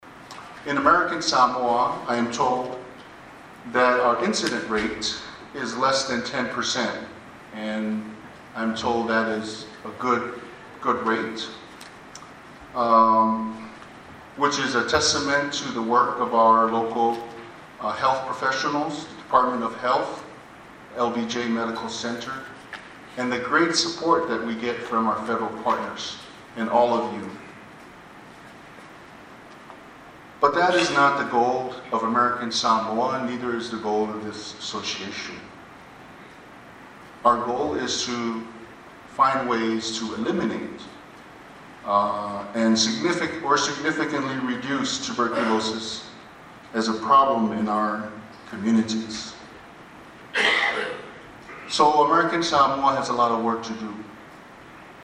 Lt. Governor Talauega Eleasalo Ale delivered the keynote address at the opening of the  annual conference of the Pacific Islands Tuberculosis Controllers Association this morning at the Fatuoaiga Auditorium.
In his remarks at the opening, Lt. Governor Talauega said while this is a low number, American Samoa‘s goal is to eliminate TB all together.